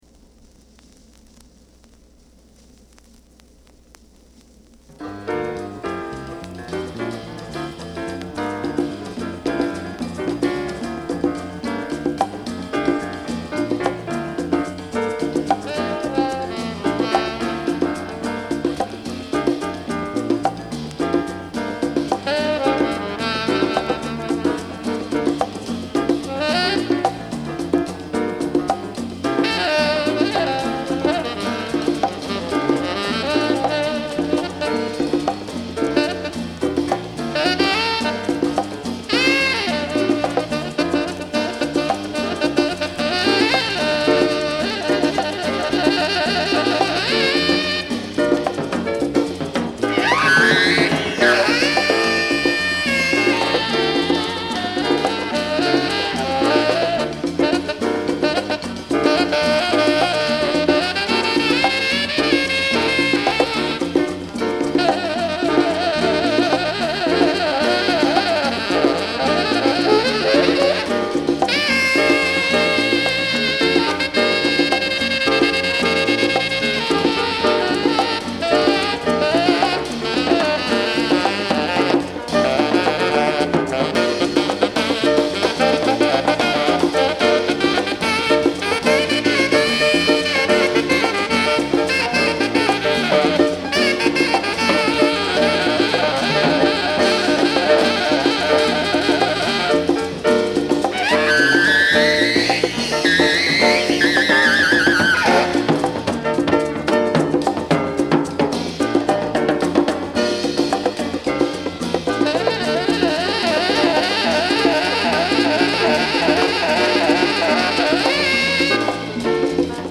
Genre: Latin Jazz / Avant-Garde Jazz Jacket
南米のフォルクローレとジャズを接続した
7分超、荒々しく土着的な情感が持続する。